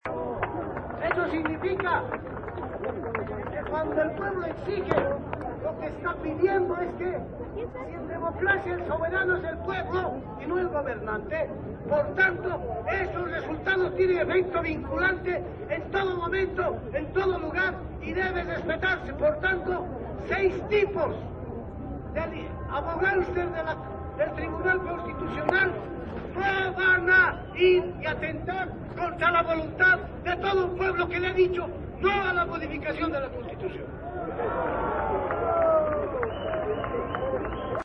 Waldo Albarracín, rector de la Universidad Mayor de San Andrés (UMSA) dijo que el gobierno promovió un referéndum, donde la población rechazó su repostulación.